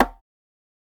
rim 14 (metro thing).wav